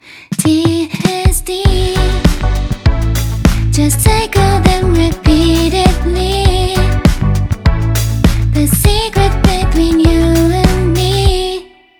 さてそれでは、ここから怒涛の視聴タイムです!! 4コードのループで、TDSの違いを感じてみましょう。今回は単一のメロディを使い回して、さまざまなコード進行をあてていくことにします。
ここまで聴いてきたI始まりの「明るくて安定的」ともVIm始まりの「暗くて安定的」とも違う、ちょっとフワッとした始まり方をしているのが分かるでしょうか？